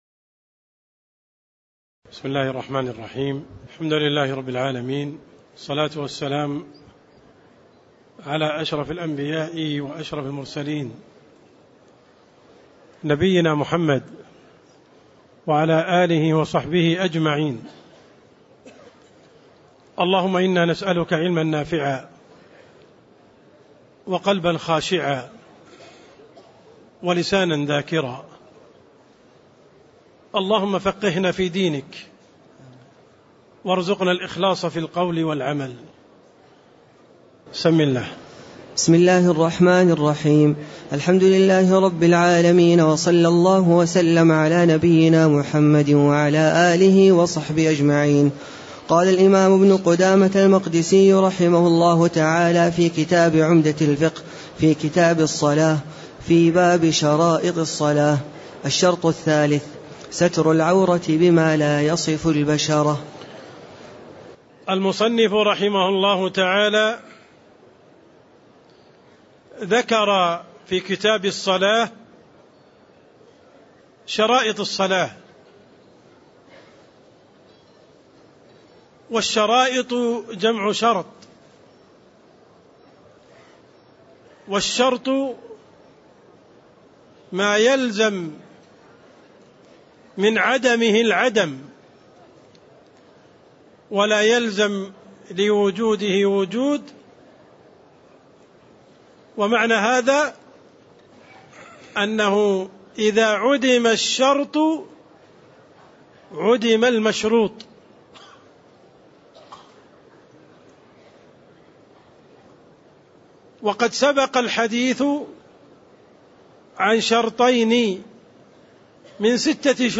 تاريخ النشر ٢٧ ذو الحجة ١٤٣٥ هـ المكان: المسجد النبوي الشيخ: عبدالرحمن السند عبدالرحمن السند باب شروط الصلاة (05)